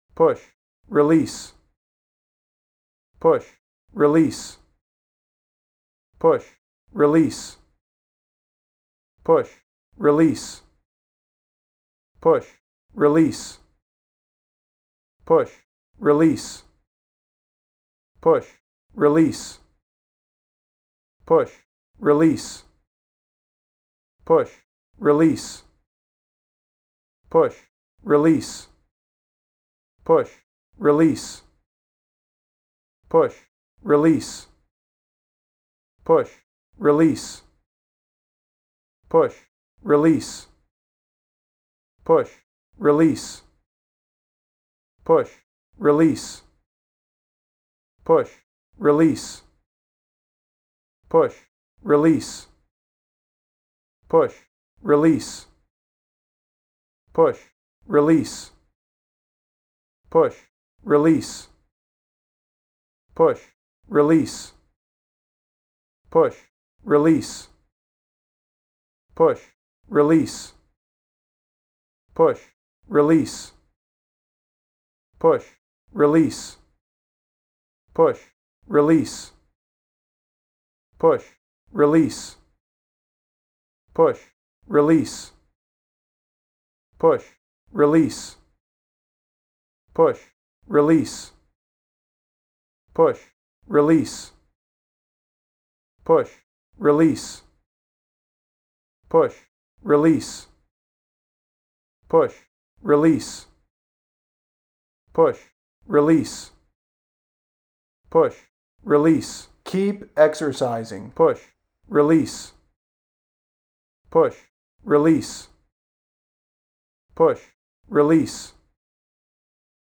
• Exercise Recording – a 2-minute pacing
track used to guide exercise timing inside the scanner. The 20 bpm audio cue ensures a consistent contraction rhythm throughout the 2-minute exercise period, critical for standardized CrCEST acquisition.
push-2min-20bpm-kpex.mp3